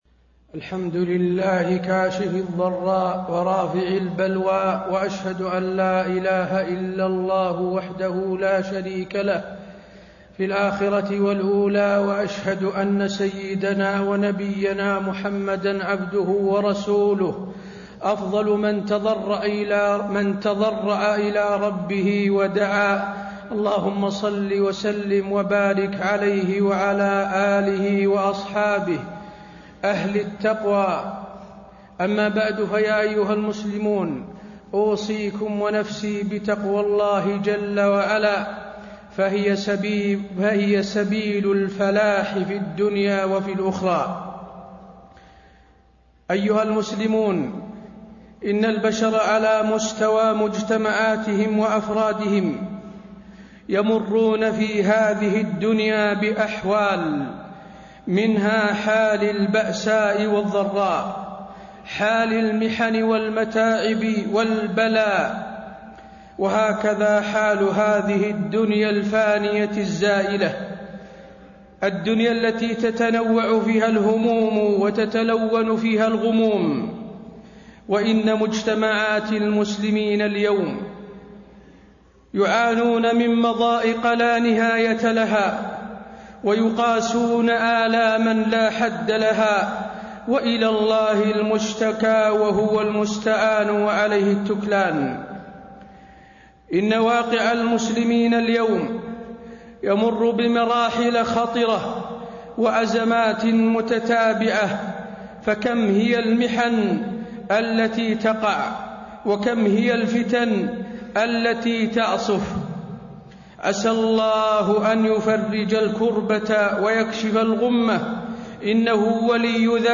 تاريخ النشر ١٦ محرم ١٤٣٤ هـ المكان: المسجد النبوي الشيخ: فضيلة الشيخ د. حسين بن عبدالعزيز آل الشيخ فضيلة الشيخ د. حسين بن عبدالعزيز آل الشيخ كيف النجاة من الواقع المر